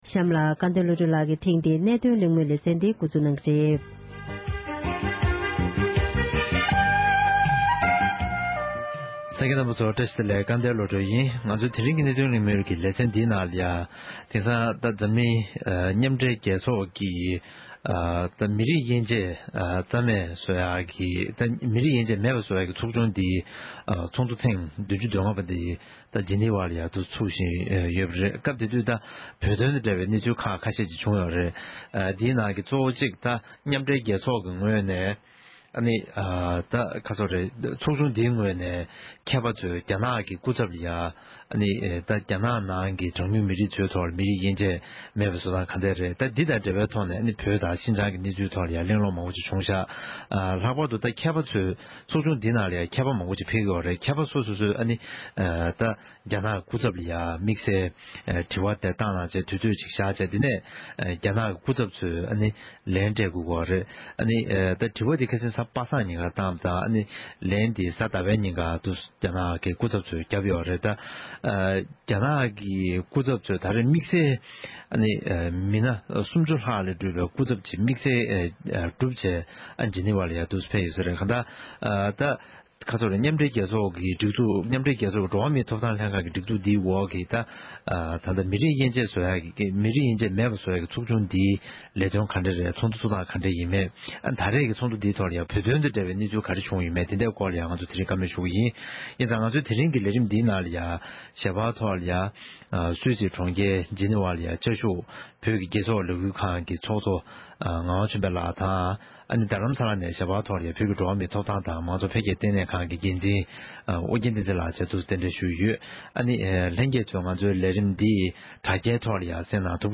འབྲེལ་ཡོད་མི་སྣ་ཁག་ཅིག་གི་ལྷན་གླེང་མོལ་ཞུས་པར་གསན་རོགས༎